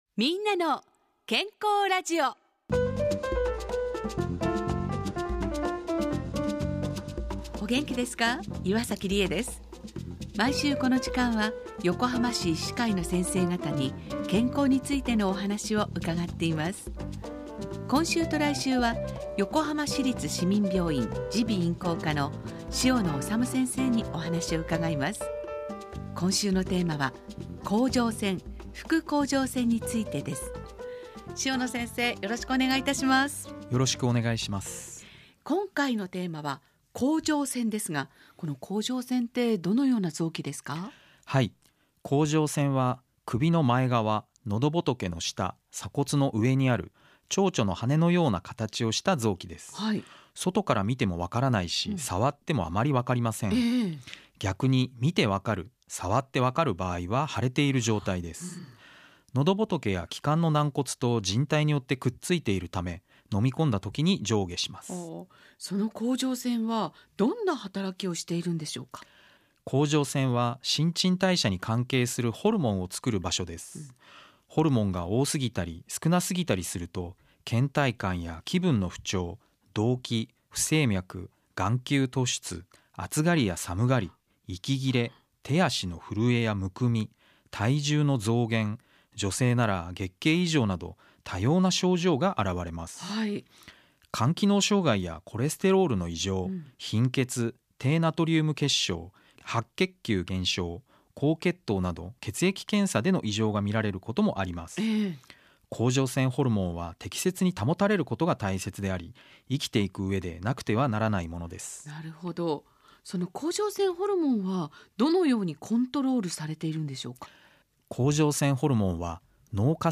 ラジオ番組 みんなの健康ラジオ